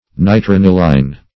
Search Result for " nitraniline" : The Collaborative International Dictionary of English v.0.48: Nitraniline \Ni*tran"i*line\ (? or ?), n. [Nitro- + aniline.] (Chem.) Any one of a series of nitro derivatives of aniline; nitroaniline.